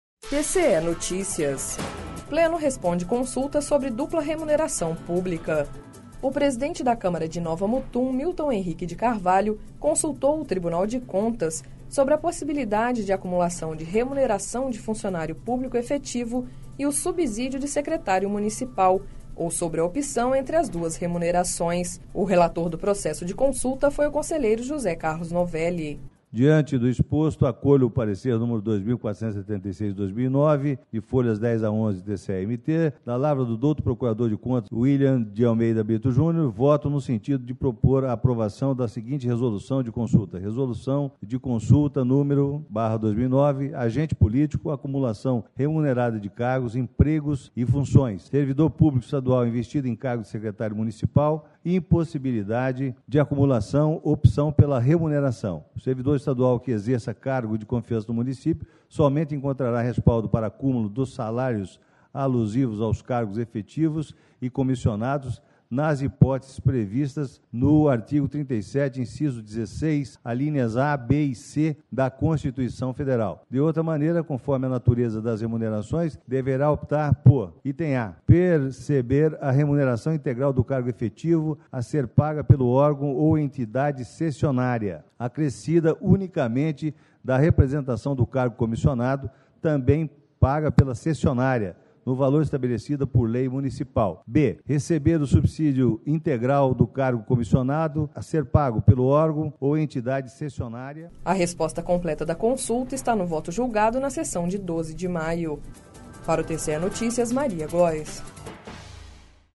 Sonora: José Carlos Novelli – conselheiro TCE-MT